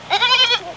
Baby Lamb Baa Ing Sound Effect
Download a high-quality baby lamb baa ing sound effect.
baby-lamb-baa-ing.mp3